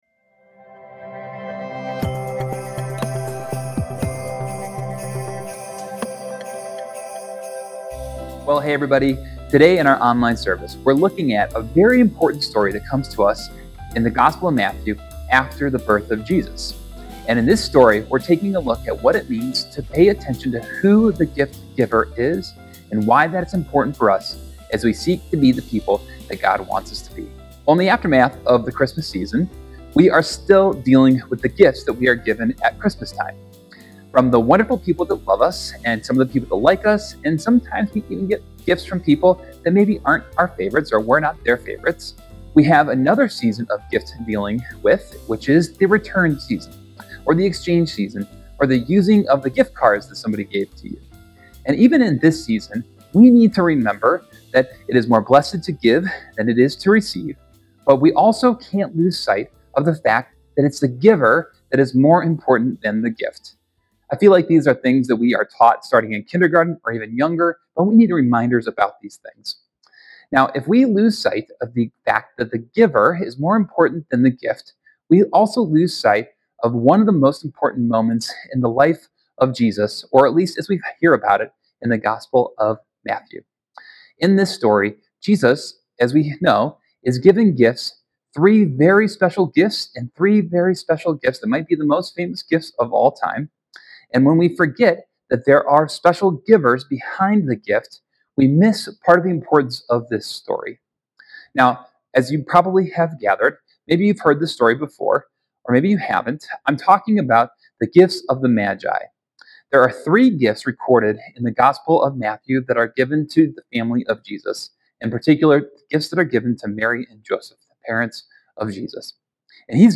Welcome back our listeners, thank you for worshiping with us today!